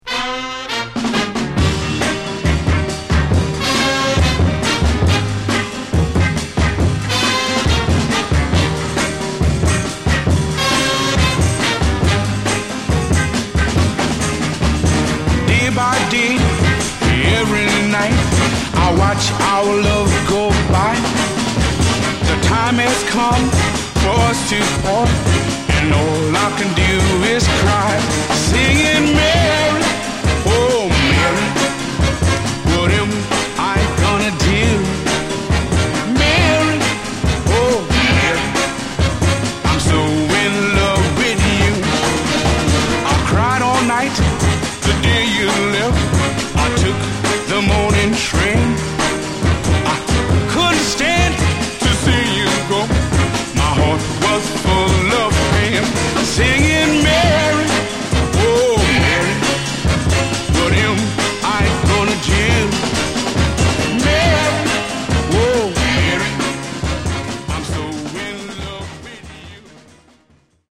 Genre: Other Northern Soul
Both sides of this single are rockin' northern soul dancers.